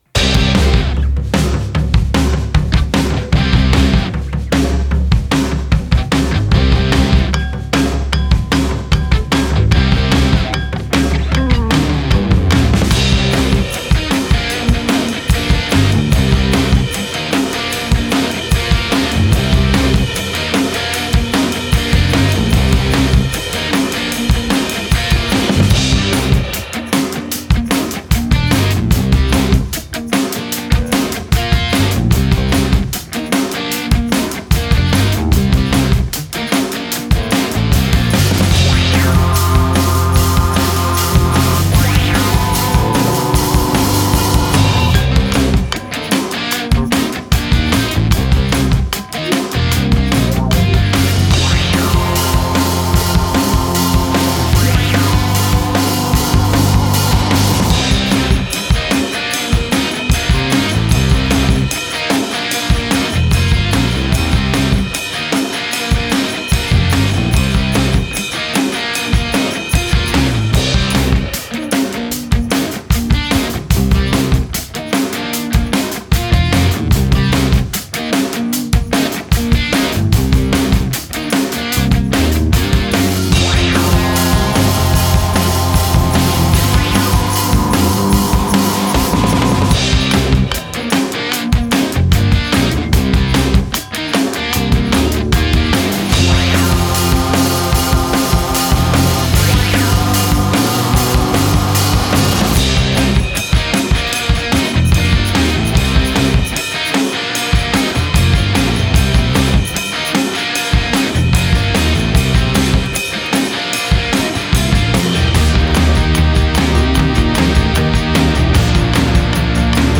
mode: lively
Genres: rock